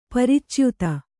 ♪ paricyuta